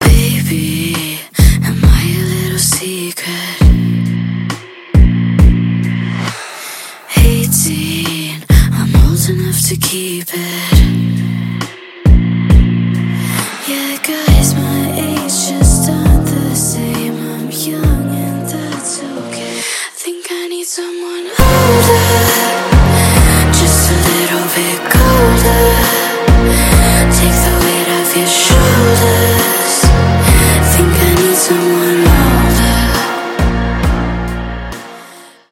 Танцевальные рингтоны
Громкие рингтоны